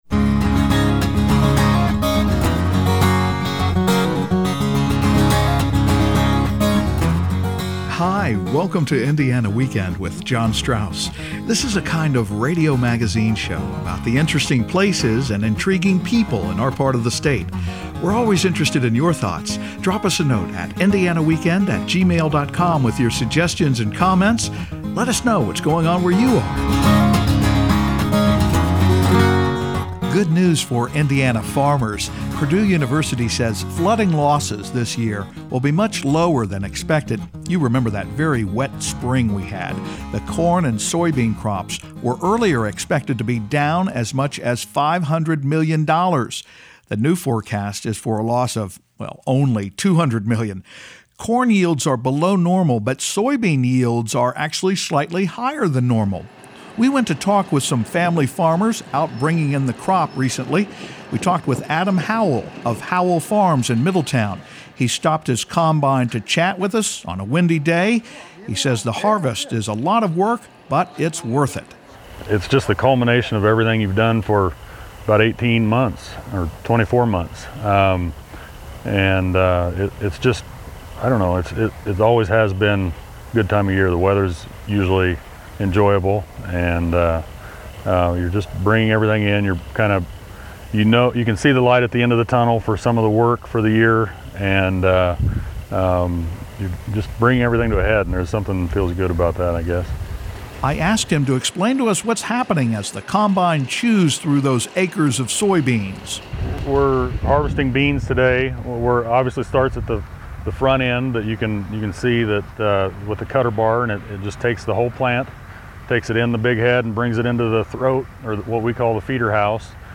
Good news for Indiana farmers – after a really wet planting season, flooding losses this year will be much lower than expected. We ride along in the combine as family farmers harvest their corn and soybean crops.